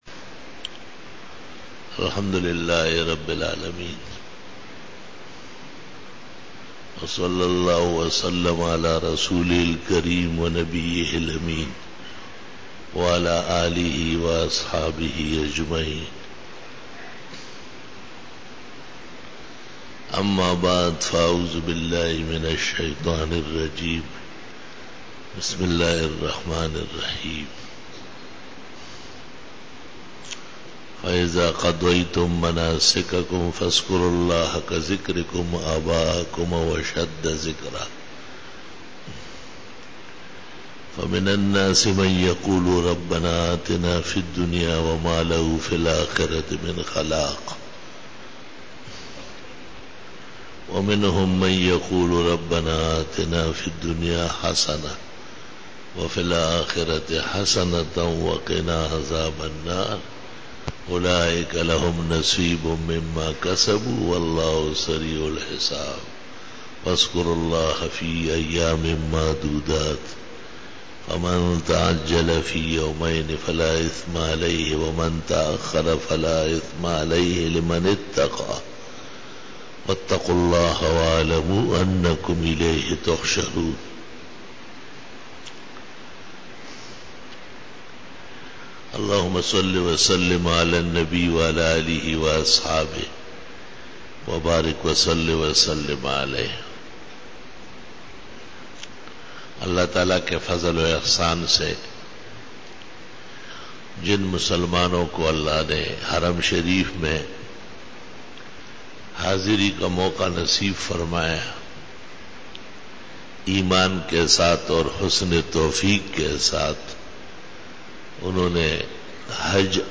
41_BAYAN E JUMA TUL MUBARAK 18-OCTOBER-2013